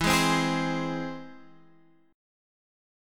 E+ chord